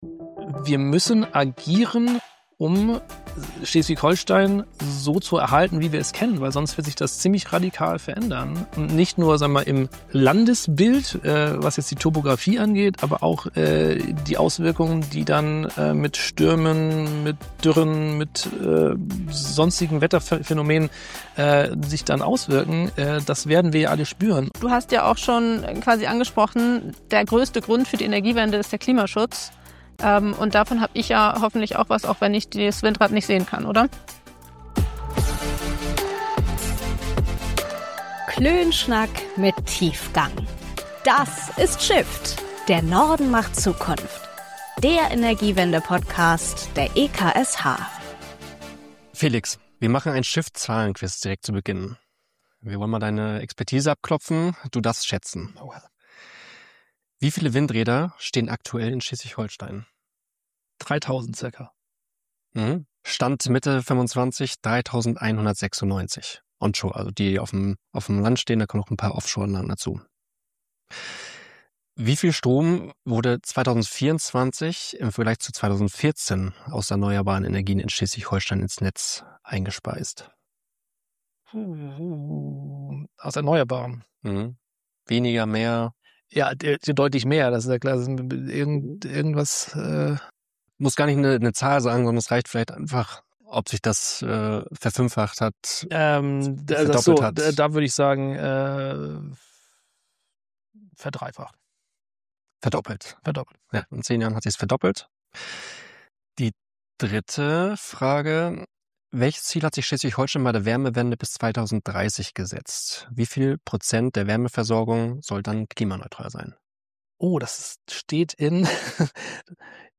Viel Spaß bei unserem Klönschnack mit Tiefgang!